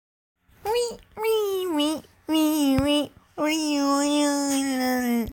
Nada dering Laughing sound
Message Tones
funny , hahaha ,